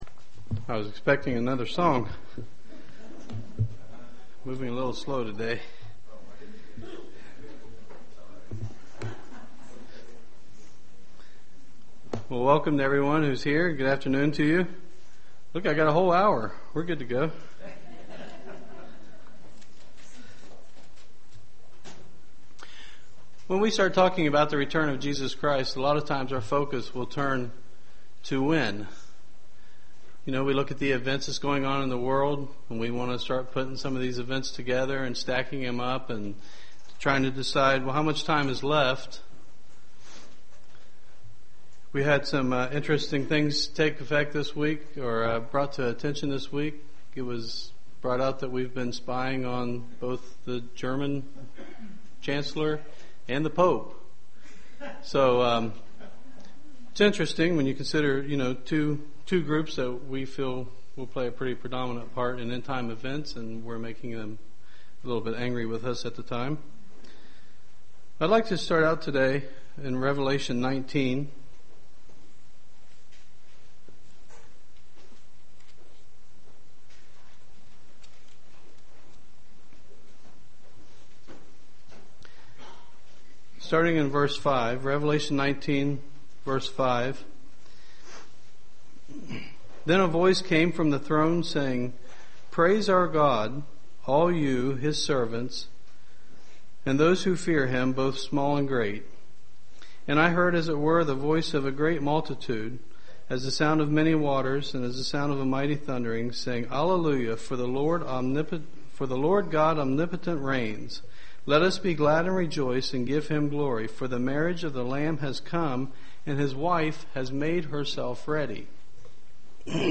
UCG Sermon Studying the bible?
Given in Dayton, OH